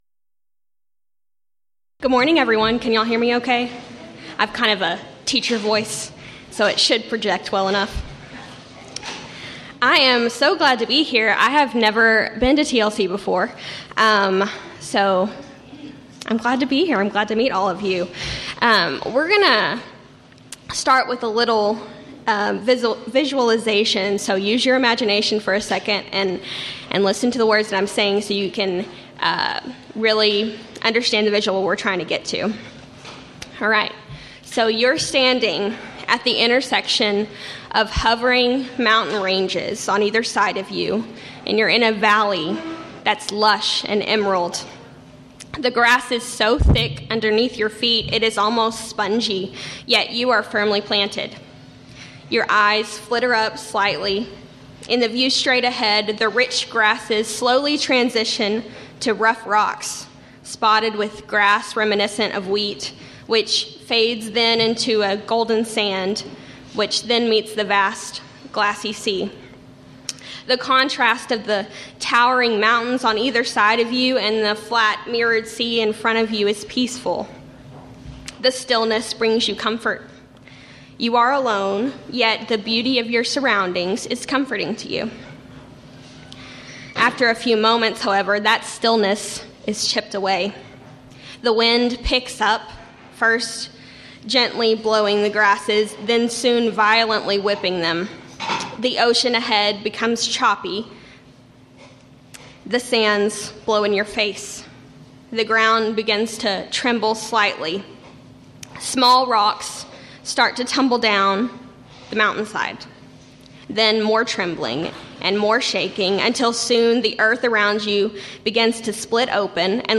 Title: Friday Morning Devotional
Event: 9th Annual Texas Ladies in Christ Retreat